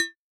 RDM_Raw_SY1-Perc01.wav